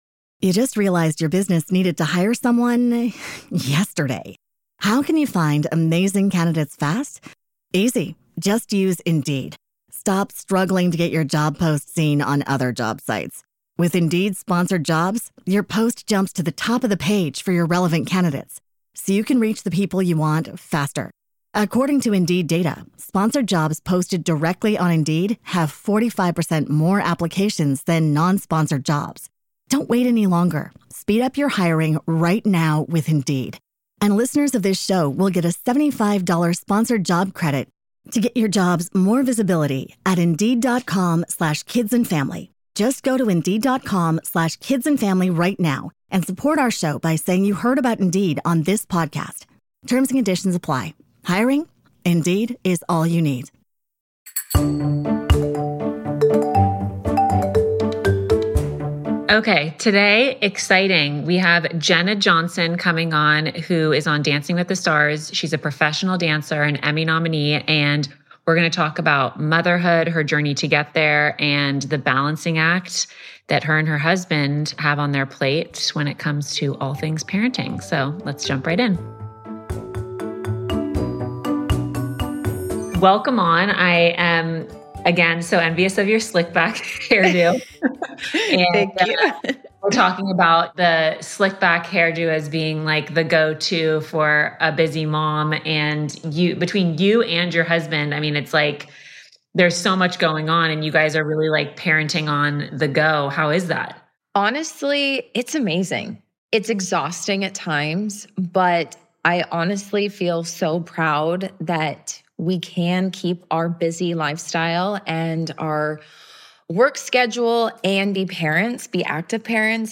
Jenna Johnson—professional dancer, Emmy nominee, and mom—joins me for a heartfelt conversation about motherhood, career, and what it really takes to do both.